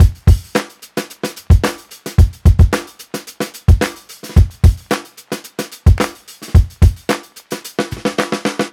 Unison Funk - 7 - 110bpm.wav